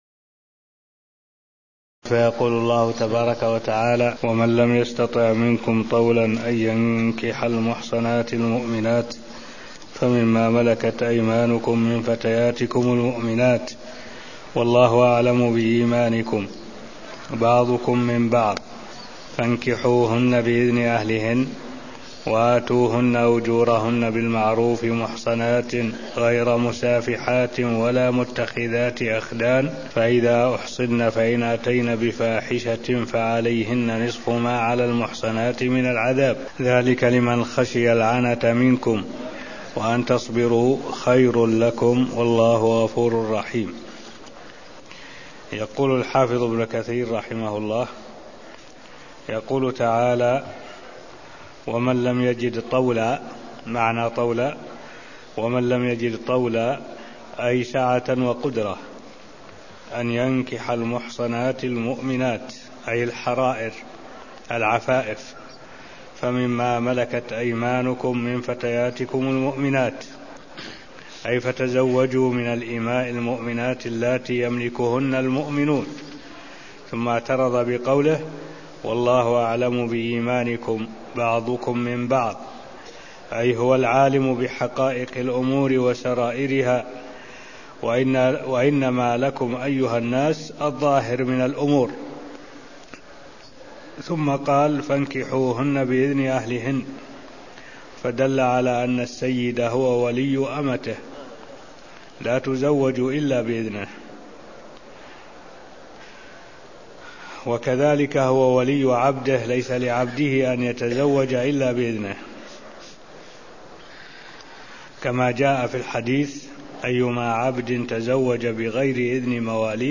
المكان: المسجد النبوي الشيخ: معالي الشيخ الدكتور صالح بن عبد الله العبود معالي الشيخ الدكتور صالح بن عبد الله العبود تفسير الآيتين 24-25 من سورة النساء (0216)